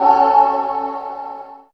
64 GUIT 3 -L.wav